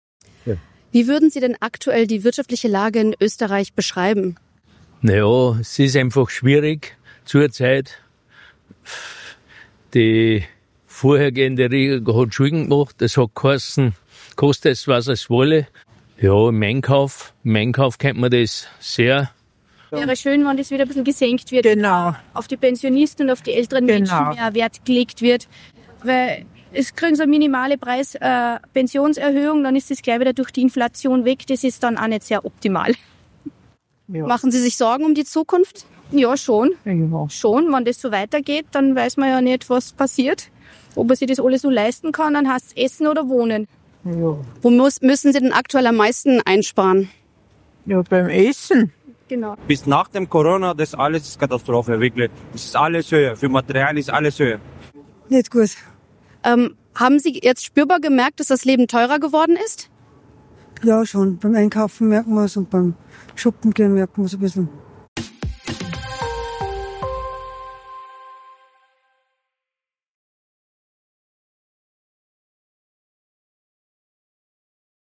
Linzer Pensionistin verzweifelt: „Jetzt muss ich schon beim Essen sparen“